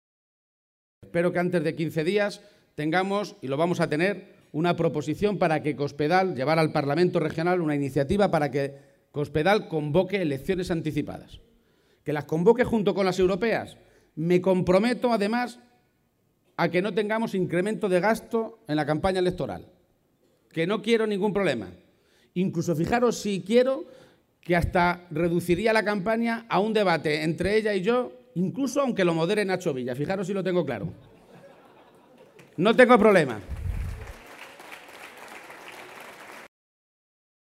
El secretario general del PSCM-PSOE presidió la tradicional comida navideña con militantes socialistas de Albacete
Audio Page Comida Navidad Albacete_211213